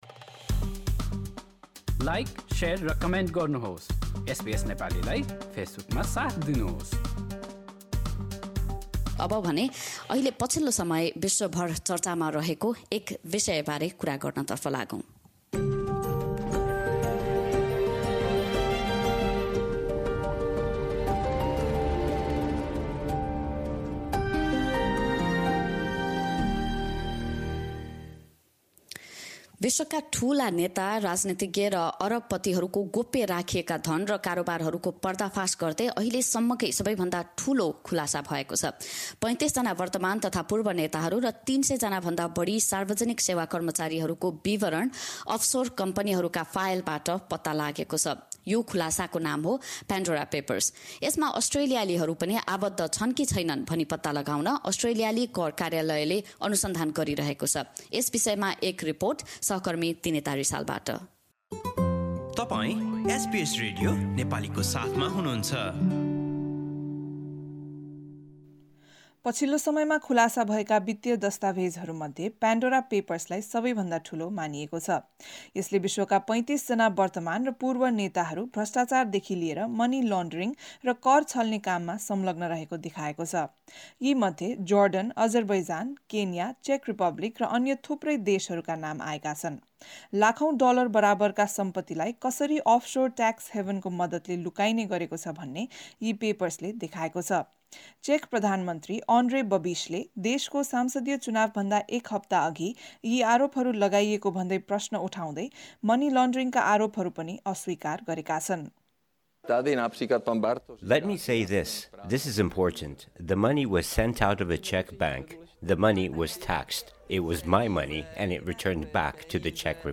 नेपालका केही व्यक्ति तथा व्यवसायहरूको नाम प्यान्डोरापेपर्समा आएको समयमा, यस अनुसन्धानमा संलग्न एक नेपाली खोज पत्रकारसँग पनि हामीले कुराकानी गरेका थियौँ।